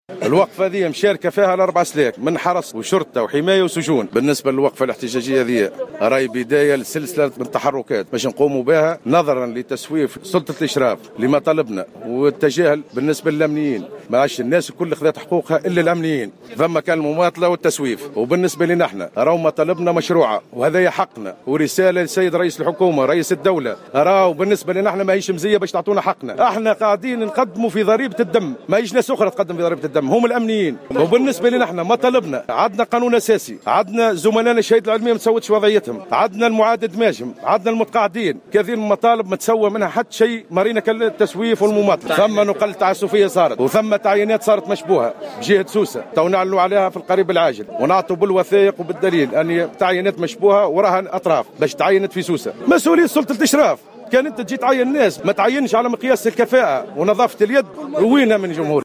على هامش وقفة احتجاجية نظمتها النقابة اليوم السبت أمام مقر اقليم الشرطة بسوسة